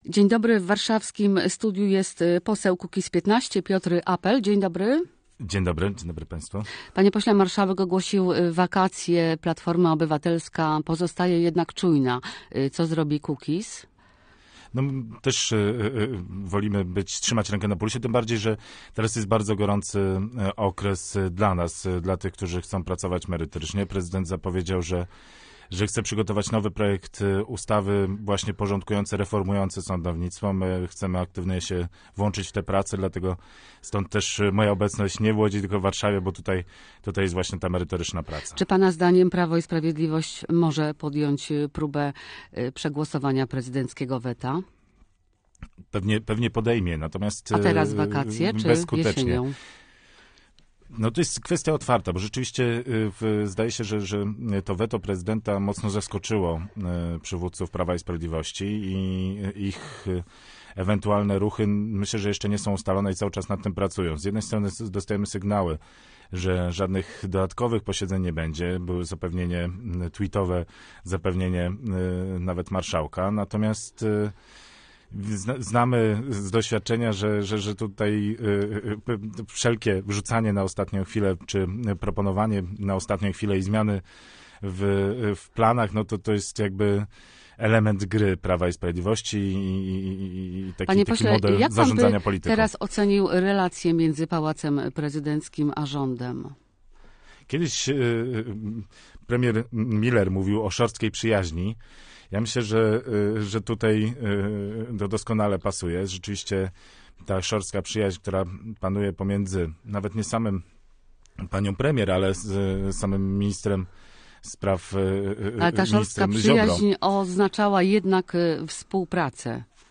Gościem Radia Łódź był poseł Kukiz'15 Piotr Apel.